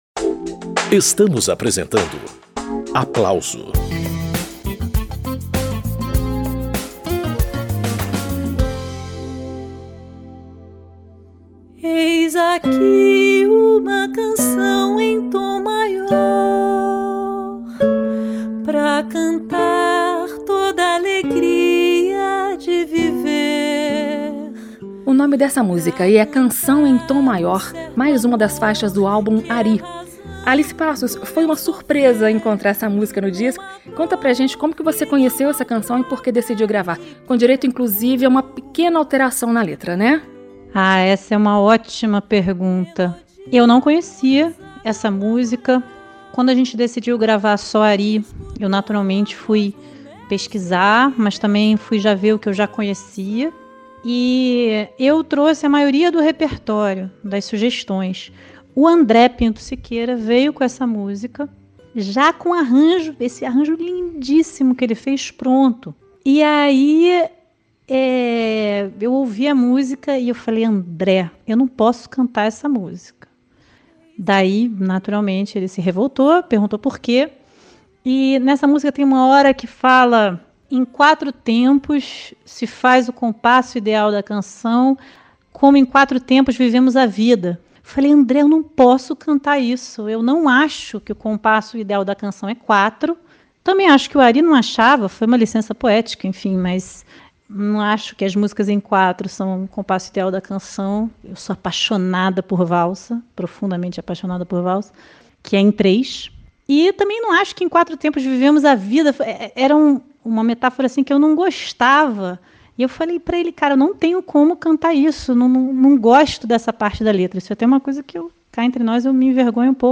Uma cantora e dois violonistas cumpriram o desafio de dar ainda mais frescor ao repertório de um dos grandes nomes da Época de Ouro do Rádio, Ary Barroso. Nesta edição do programa Aplauso